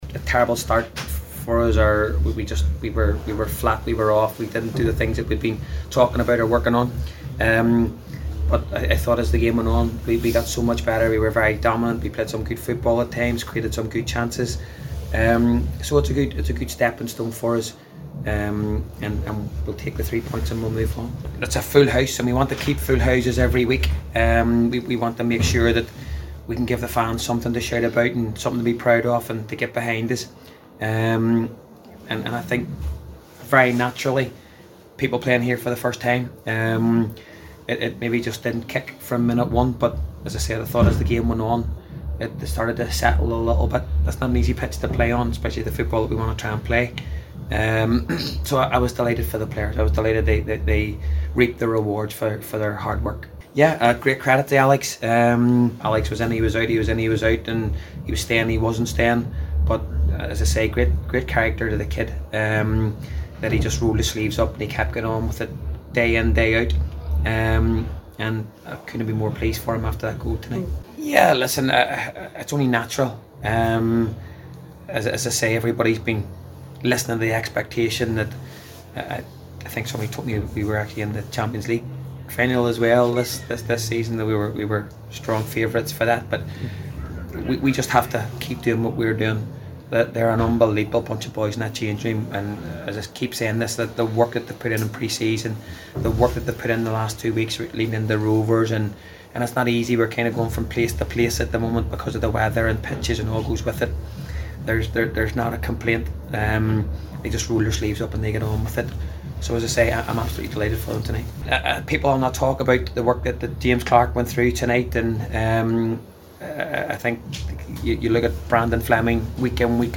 spoke to the assembled press and said it’s a good stepping stone…